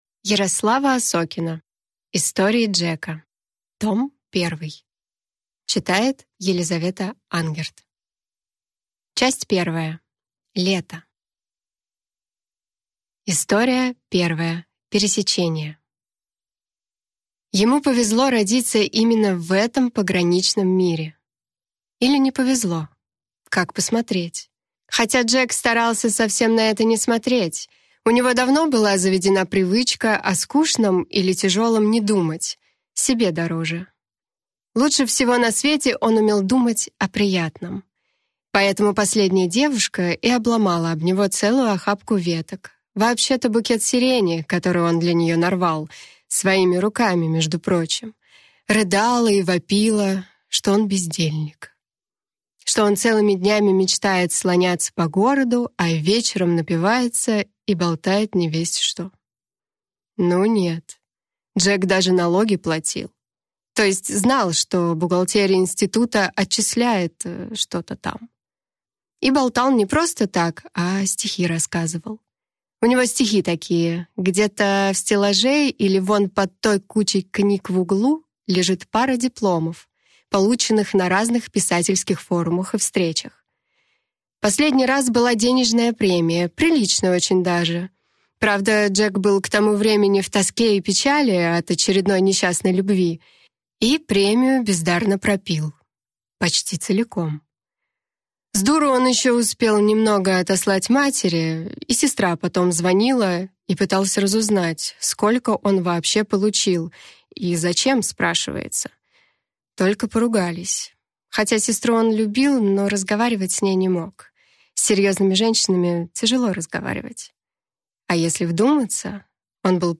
Аудиокнига Истории Джека. Том 1 | Библиотека аудиокниг